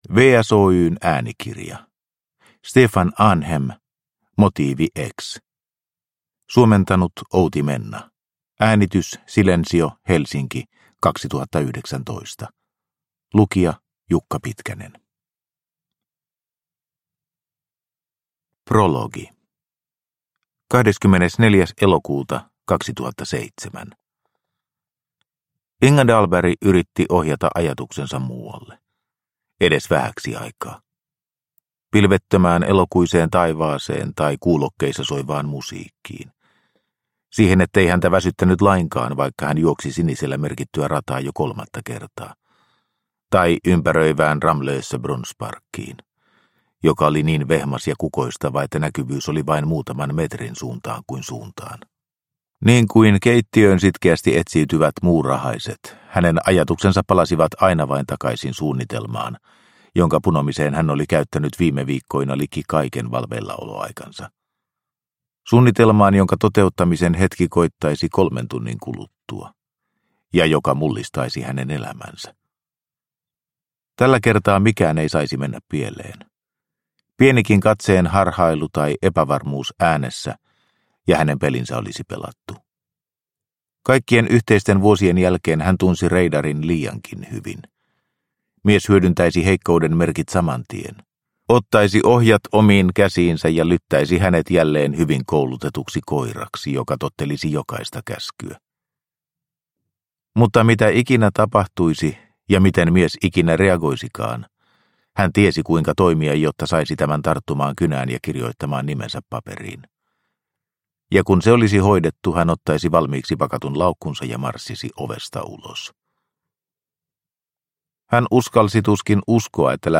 Motiivi X – Ljudbok – Laddas ner